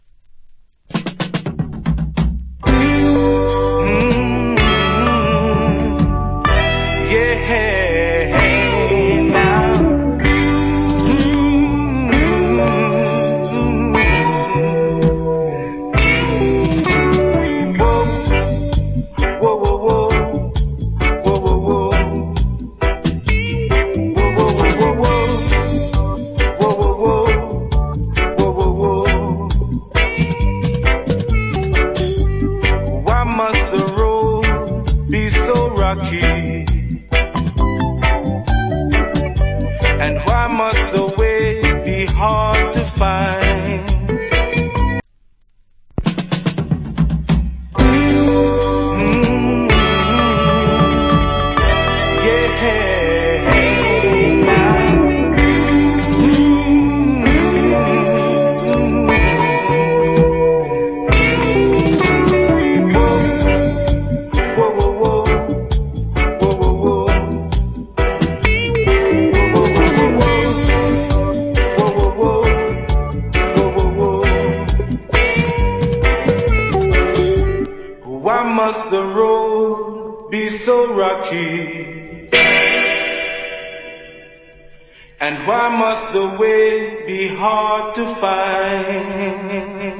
コメント Nice Reggae Vocal. / Good Dub.